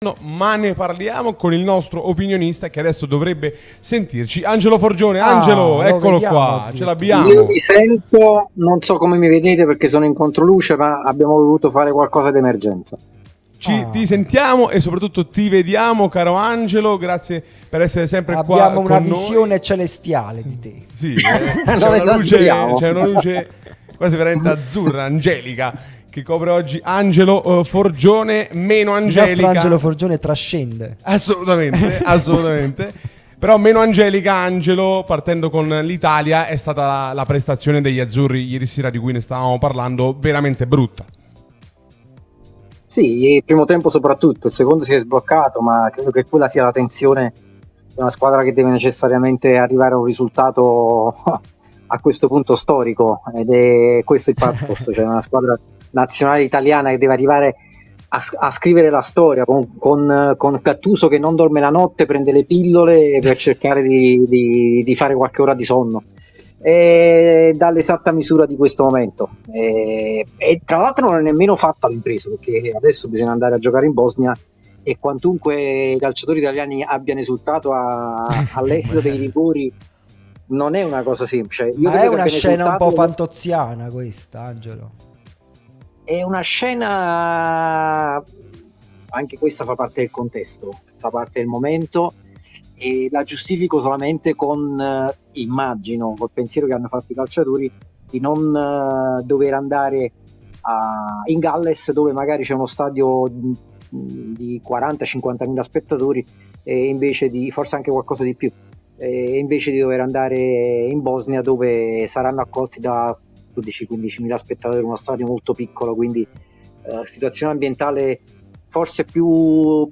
giornalista e scrittore, è stato nostro ospite su Radio Tutto Napoli